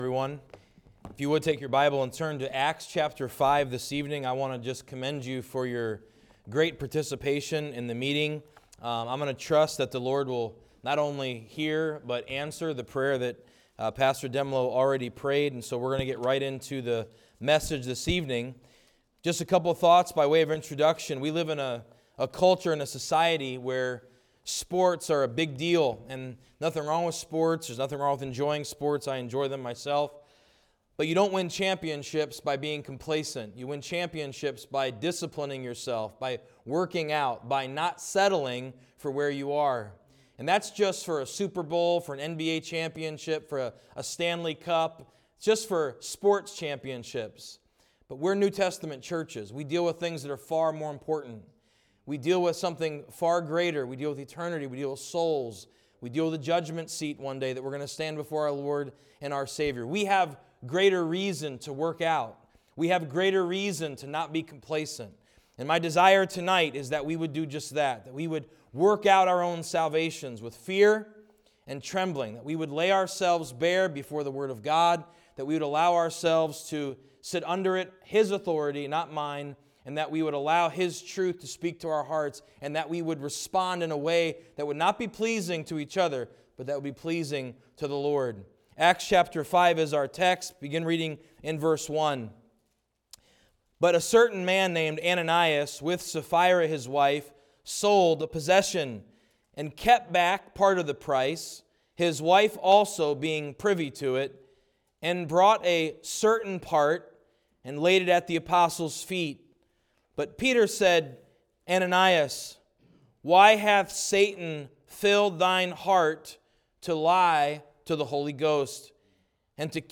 Passage: Acts 5:1-11 Service Type: Winter Conference